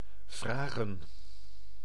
Ääntäminen
Synonyymit verlangen eisen behoeven vergen informeren verzoeken rekenen uitnodigen inroepen aanzoeken vorderen aanvragen inviteren noden Ääntäminen : IPA: [vɾa.ɣǝː] Tuntematon aksentti: IPA: /vraːɣə(n)/